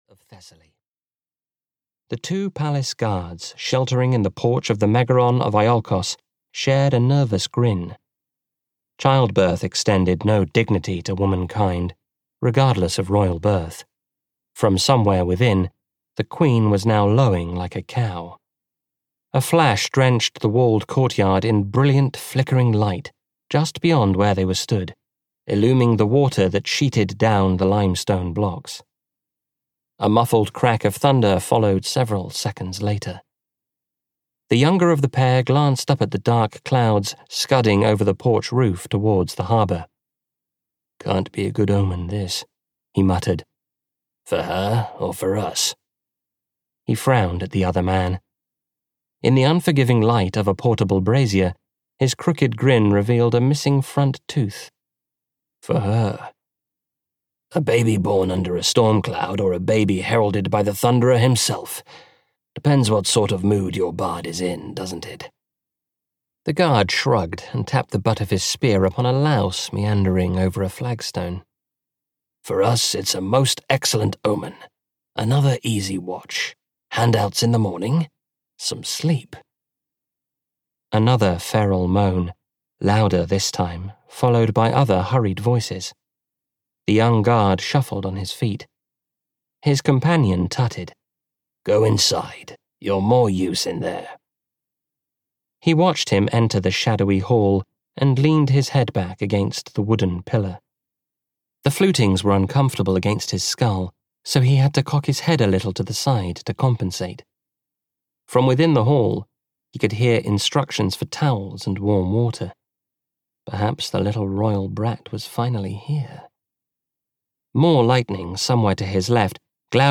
Argo (EN) audiokniha
Ukázka z knihy